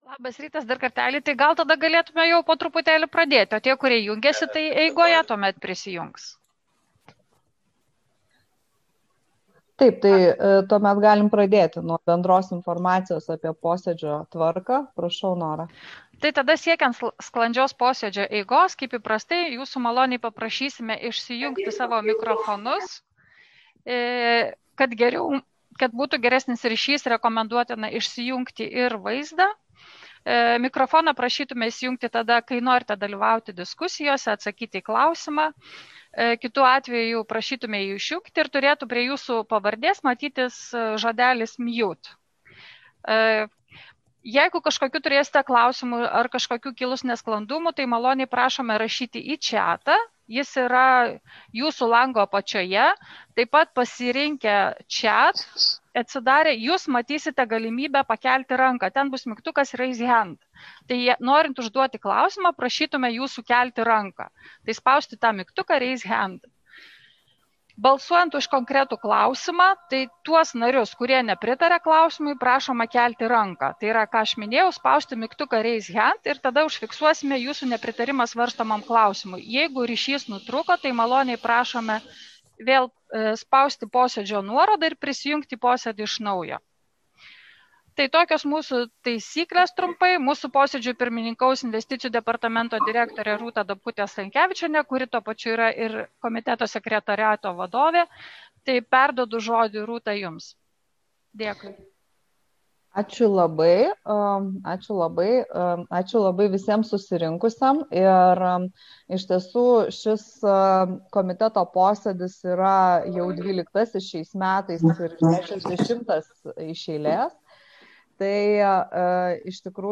2014–2020 m. ES fondų investicijų veiksmų programos stebėsenos komiteto posėdis (65)